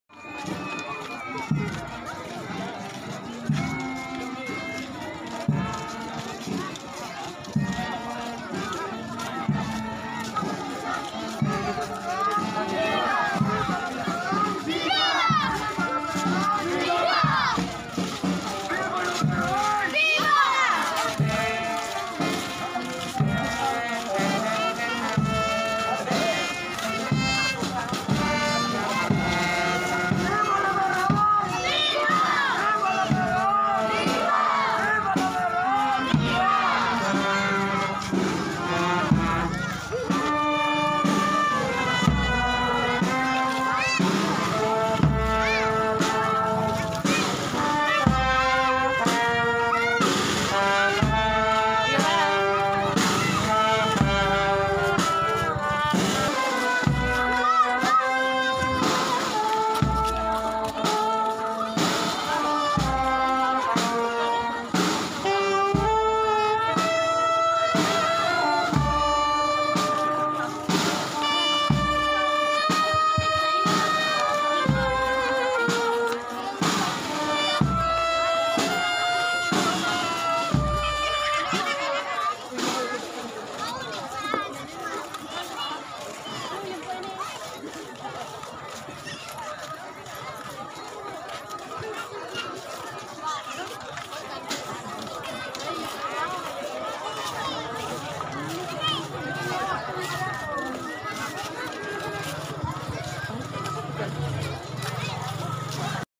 4:00 nin hapon nangyari Traslacion and Fluvial Procession ni Virgen de Pen̈afrancia asin Divino Rostro sa Barangay Tagas, San Jose.